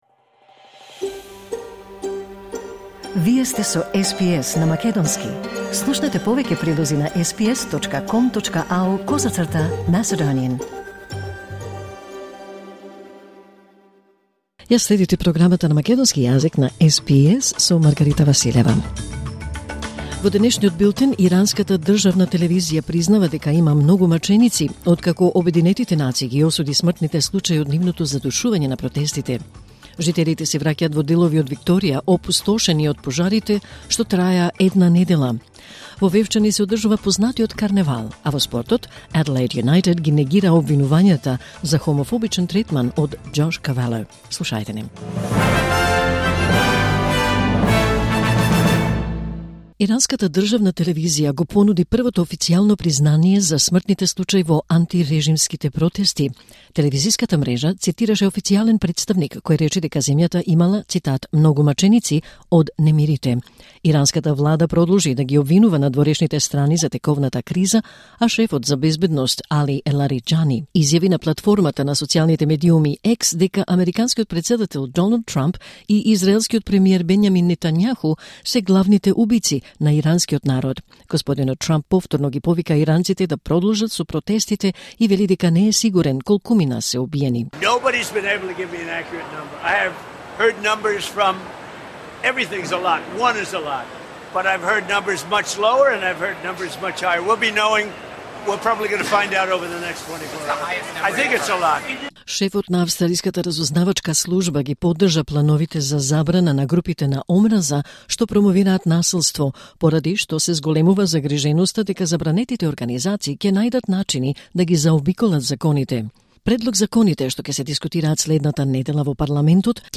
Вести на СБС на македонски 14 јануари 2026